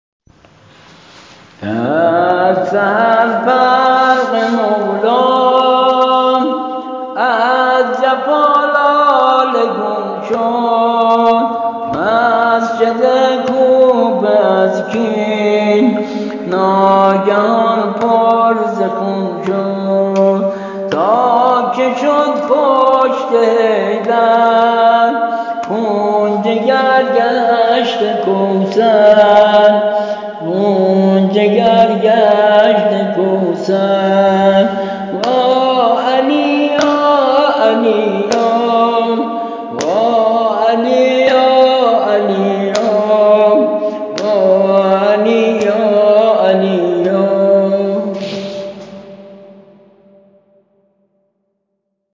نوحه سینه زنی شب نوزدهم ماه رمضان ضربت خوردن مولا(ع)